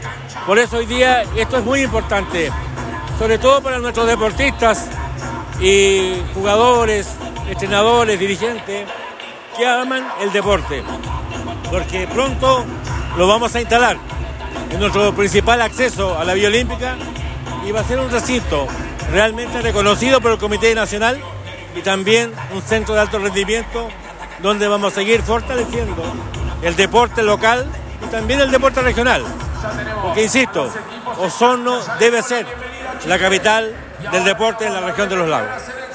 26-septiembre-24-emeterio-carrillo-recintos.mp3